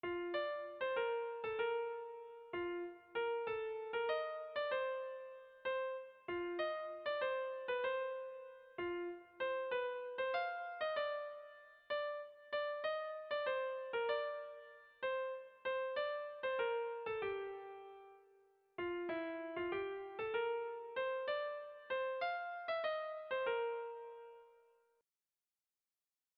Hispano errege berri - Bertso melodies - BDB.
Zortziko txikia (hg) / Lau puntuko txikia (ip)
ABDE